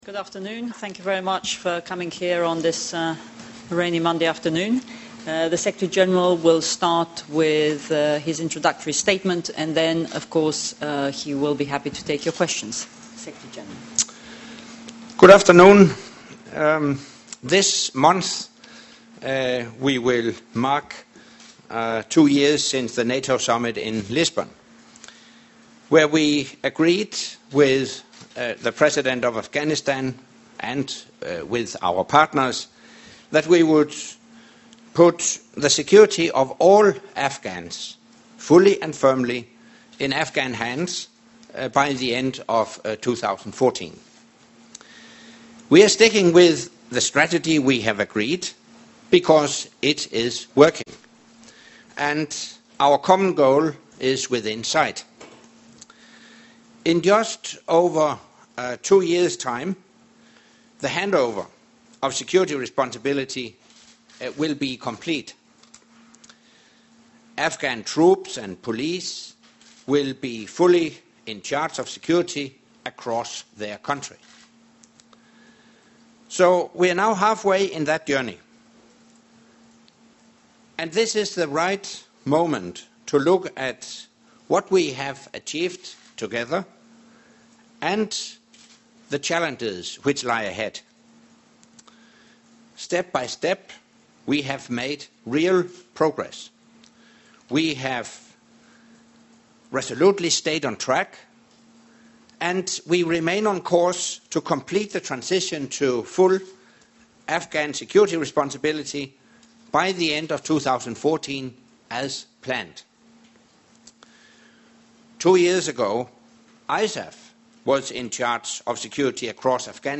Monthly press conference by the NATO Secretary General (full transcript)
Monthly press briefing by NATO Secretary General Anders Fogh Rasmussen